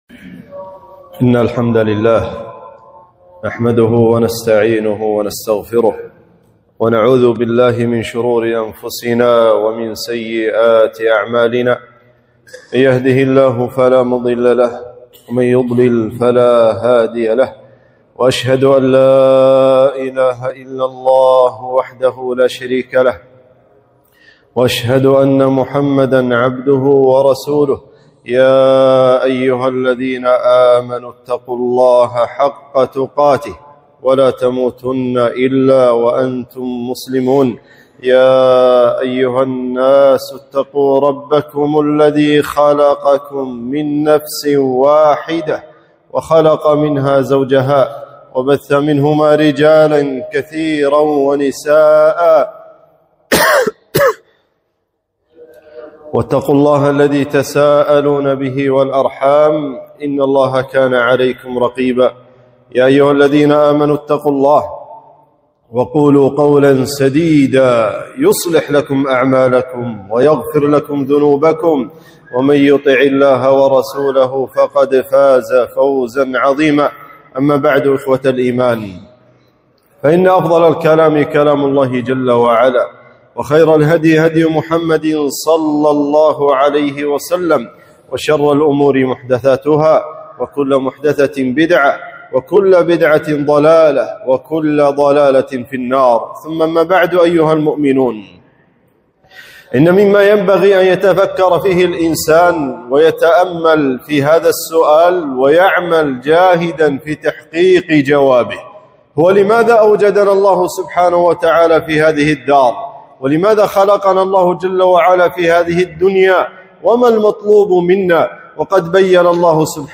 خطبة - حق الله على عباده